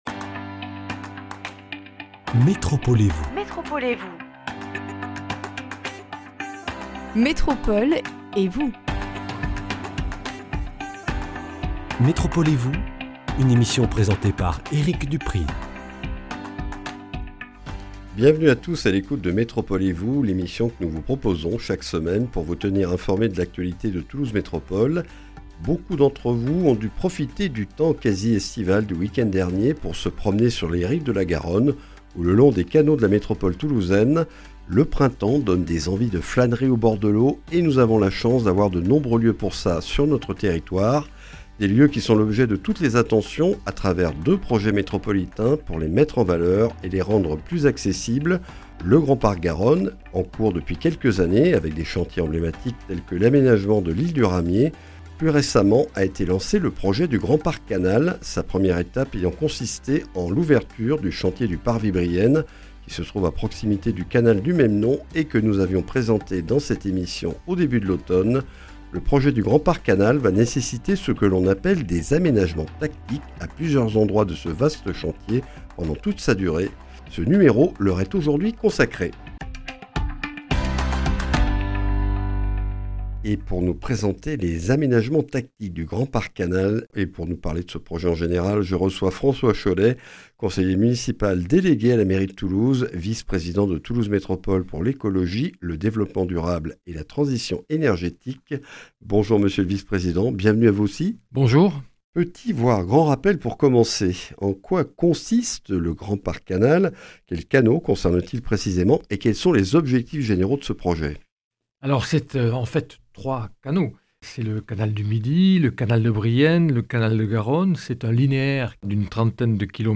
Après le chantier du Parvis Brienne - écluse Saint-Pierre, les aménagements tactiques sont la 2e étape du Grand Parc Canal sur les 8 autres sites du projet. Une démarche transitoire et participative que nous présente François Chollet, conseiller municipal délégué à la mairie de Toulouse, vice-président de Toulouse Métropole chargé du Développement durable, de l’Écologie et de la Transition énergétique.